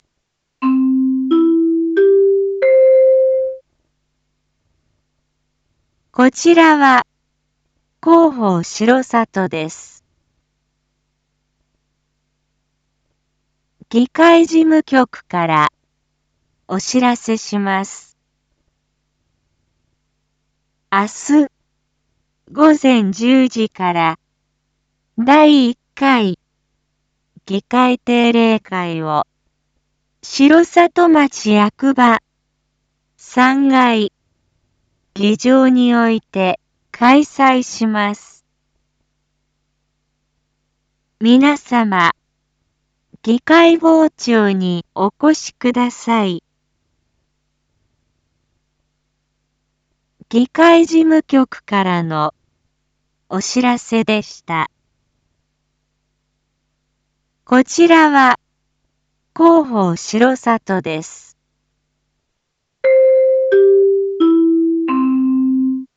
一般放送情報
Back Home 一般放送情報 音声放送 再生 一般放送情報 登録日時：2024-03-04 19:01:07 タイトル：第１回議会定例会 インフォメーション：こちらは広報しろさとです。